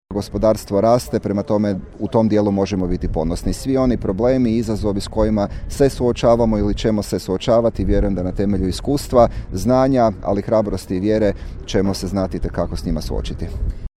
Svečana sjednica Skupštine Međimruske županije održana u atriju Staroga grada u Čakovcu bila je središnji događaj obilježavanja Dana Međimurske županije.
Matija Posavec, župan Međimurske županije: